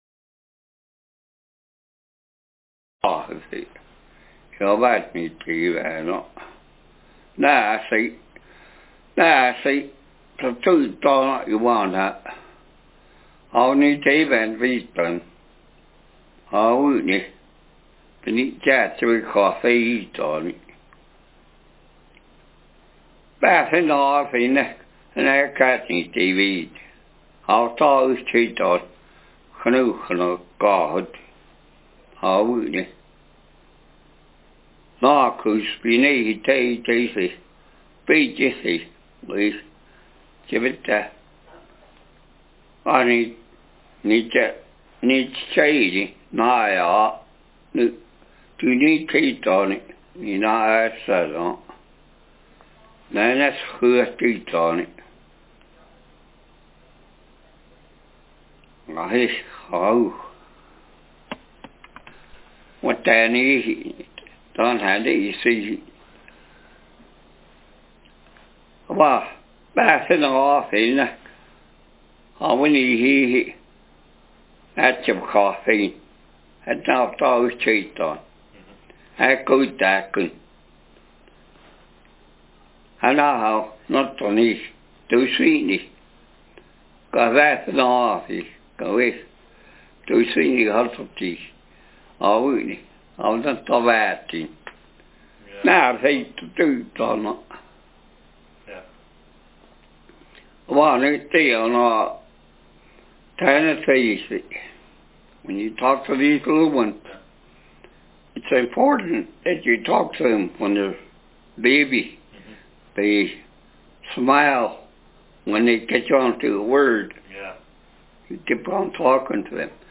Speaker sexm
Text genreconversation